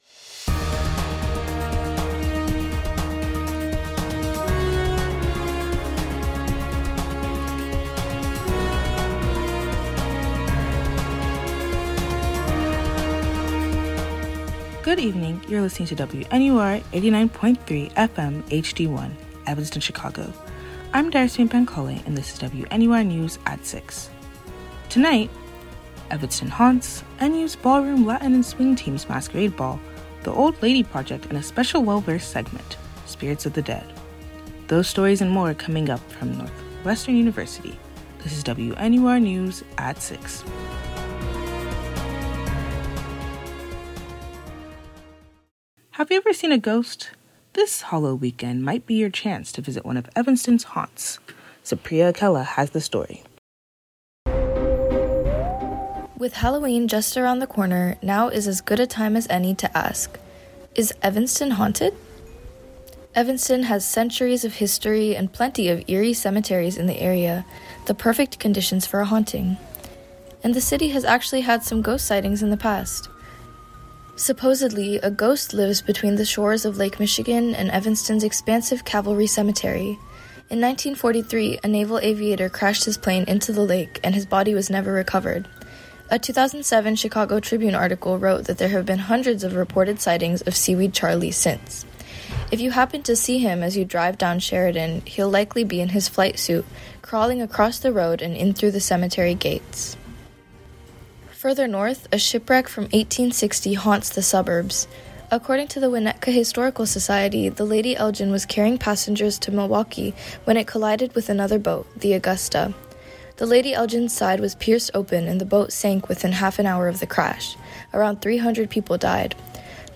October 29, 2025 Evanston haunts, NU’s Ballroom Latin and Swing Team’s masquerade ball, “The Old Lady Project,” and a special well versed segment: spirits of the dead. WNUR News broadcasts live at 6 pm CST on Mondays, Wednesdays, and Fridays on WNUR 89.3 FM.